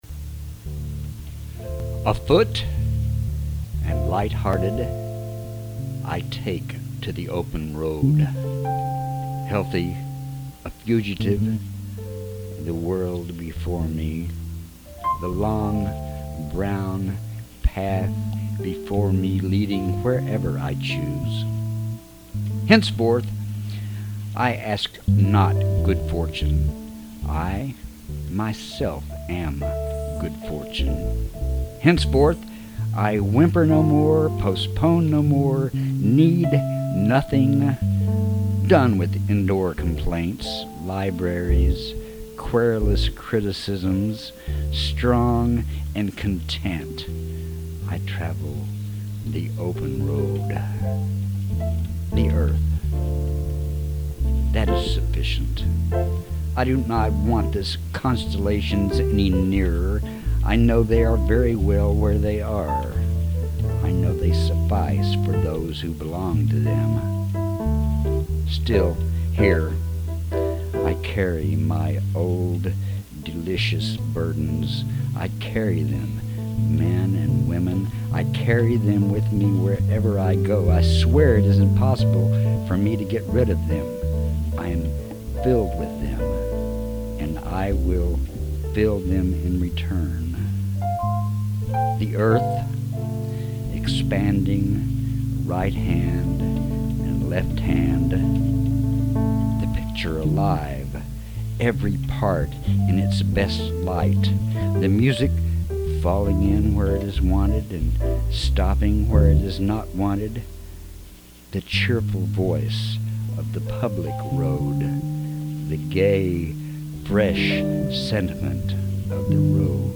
even with the cuts I ran out of recording space, that's why it ends abruptly.
mid-sentence stop caught me unawares
cool. love the piano in the background